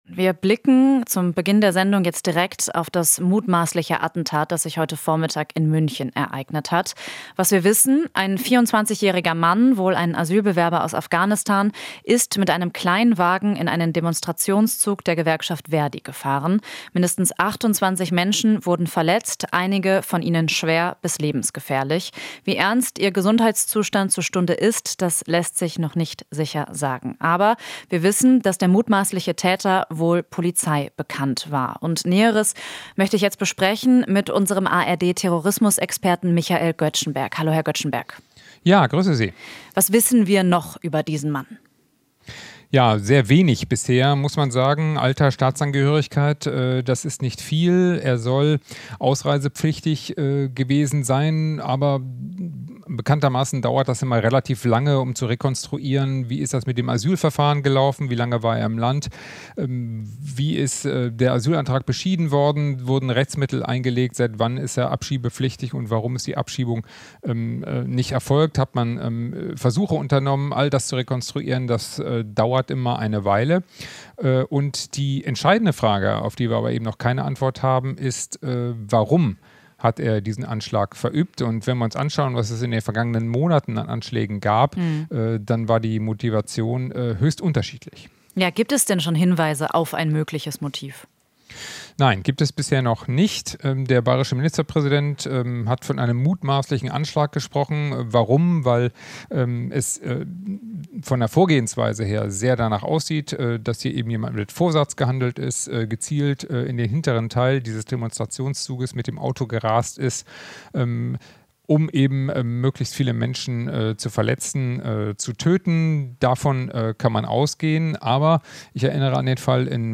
Interview - Polizei: Mindestens 28 Verletzte bei Anschlag in München